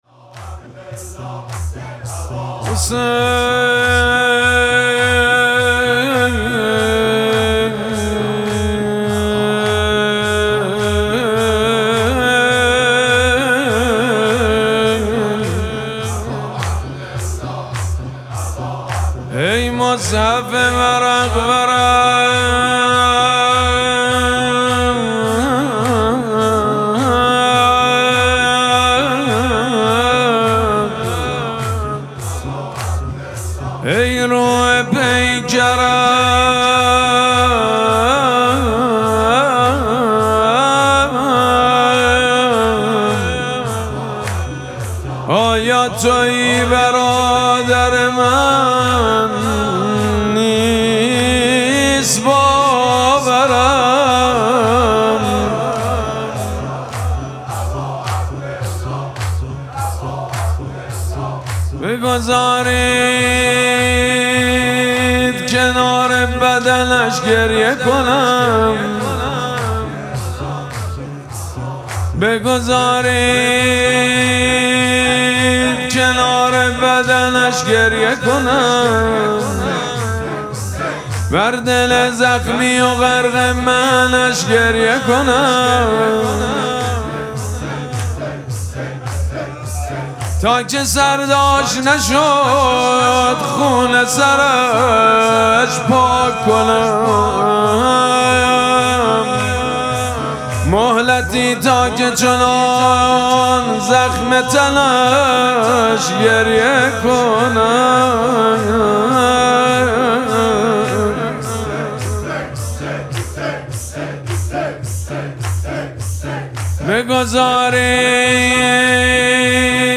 مراسم مناجات شب چهارم ماه مبارک رمضان
حاج سید مجید بنی فاطمه